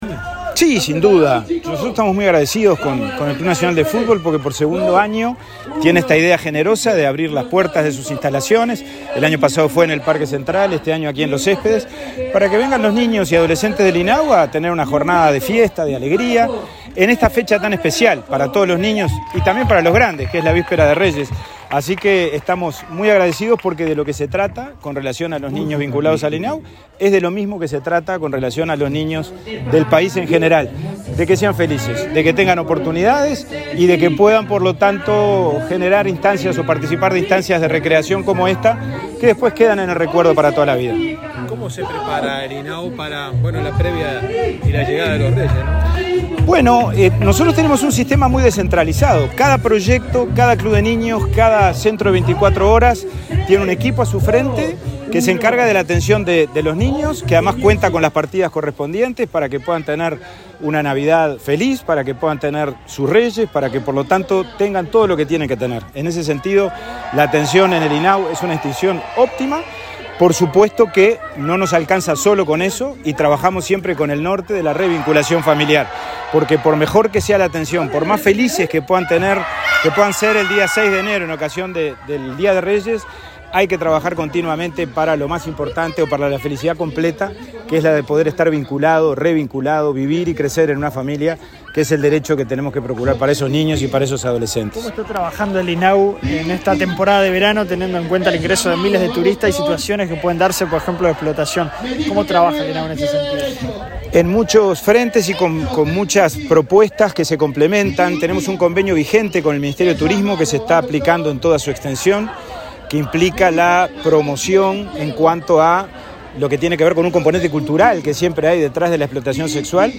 Declaraciones del presidente del INAU, Pablo Abdala
El presidente del Instituto del Niño y Adolescente del Uruguay (INAU), Pablo Abdala, dialogó con la prensa en Los Céspedes, donde niños y adolescentes